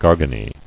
[gar·ga·ney]